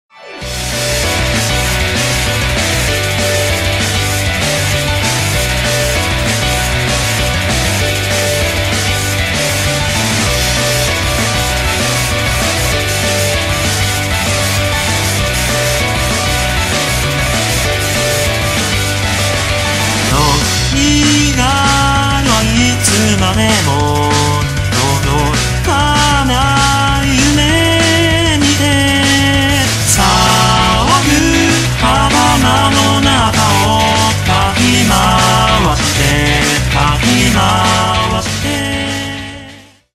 Вокалоиды